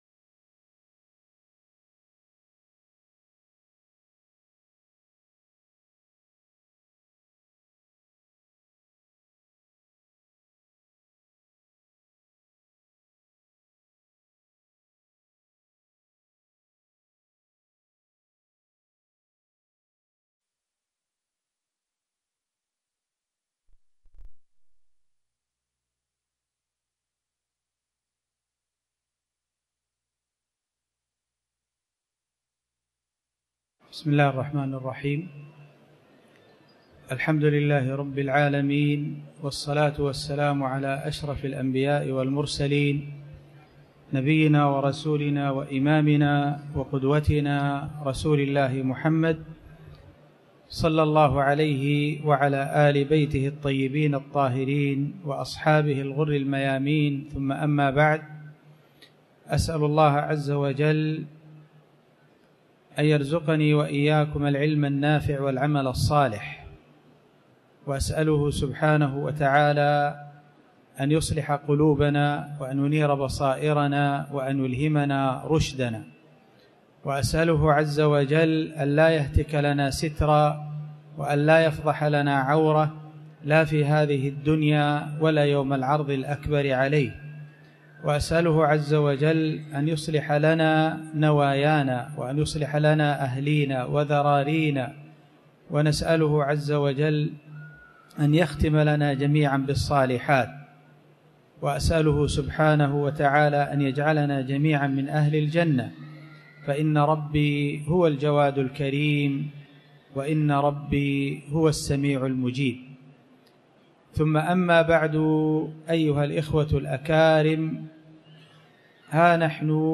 تاريخ النشر ١١ ربيع الأول ١٤٤٠ هـ المكان: المسجد الحرام الشيخ